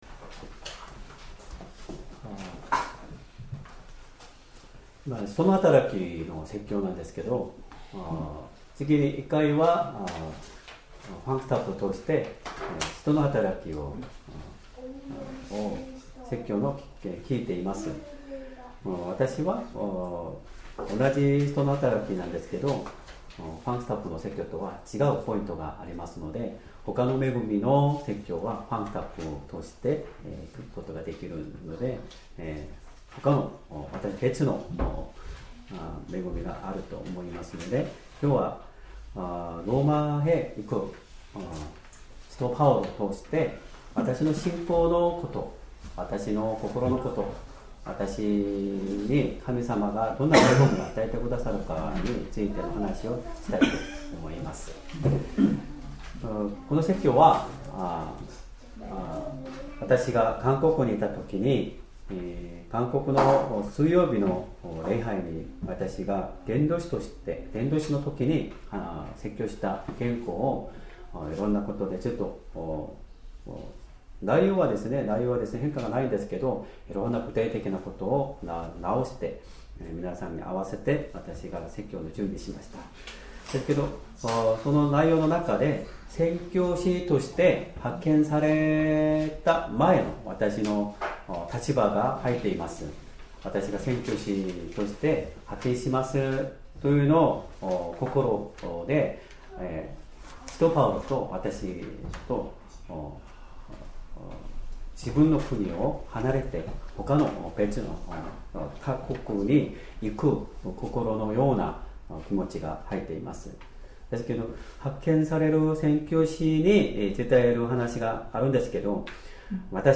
Sermon
2025年10月12日 主日礼拝 説教 「ローマへの道」 聖書 使徒の働き 22章30節 - 23章11節 22:30 翌日、千人隊長は、パウロがなぜユダヤ人たちに訴えられているのか、確かなことを知りたいと思い、彼の鎖を解いた。